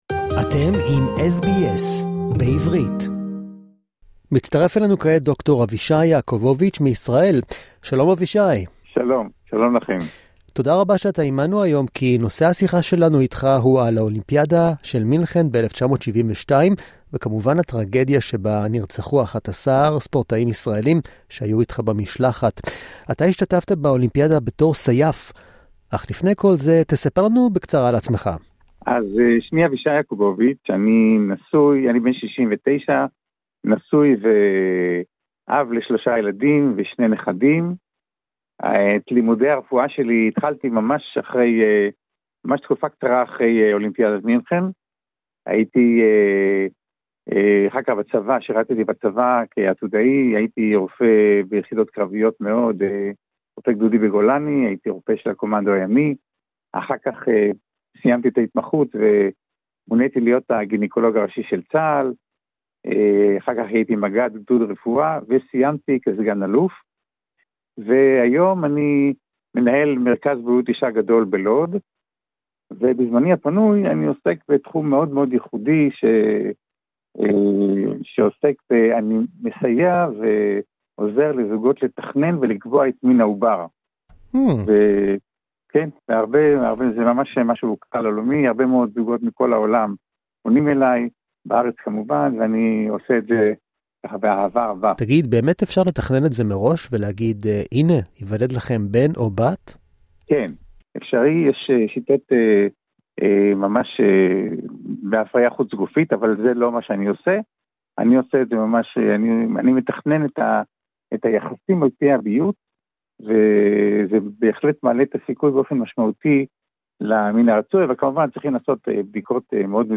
for arranging this interview!